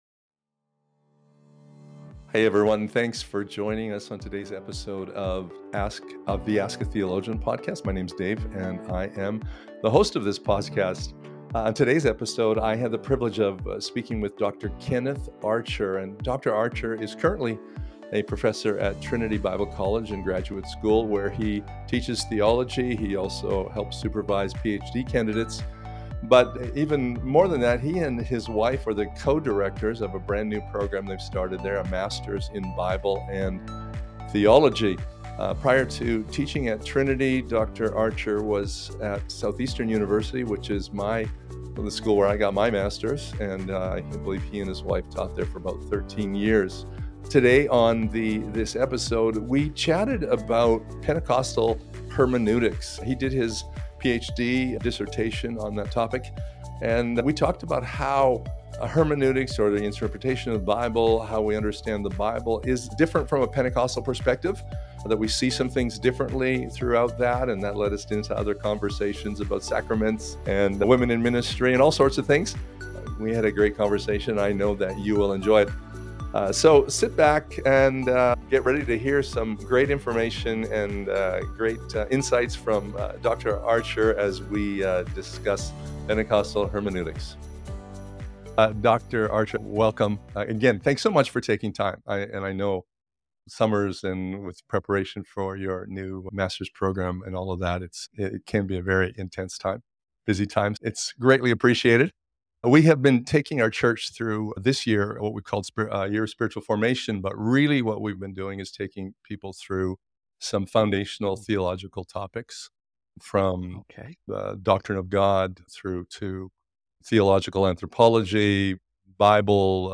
They also touch on challenges in modern theological discussions, such as same-sex marriage and the influence of cultural contexts. The conversation includes practical insights into the evolving dynamics within Pentecostalism and its broader theological implications. https